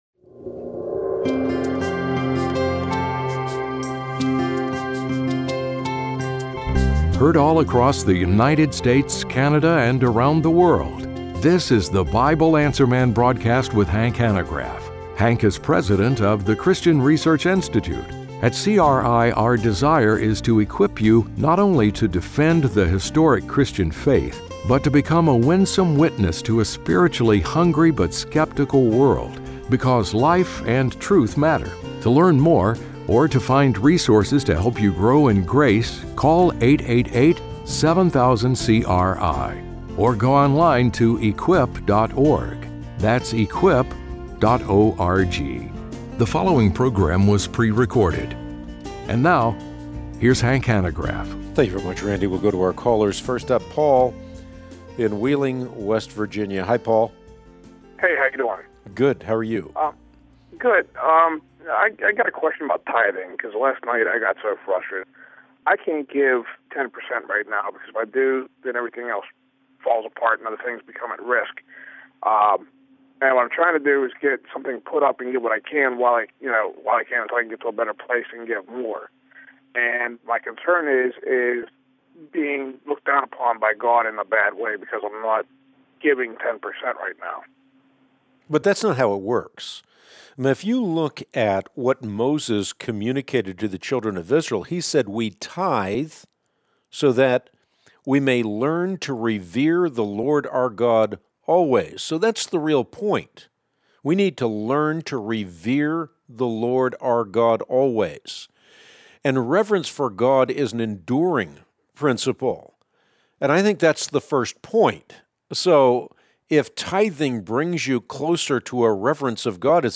On today’s Bible Answer Man broadcast (03/18/25), Hank answers the following questions: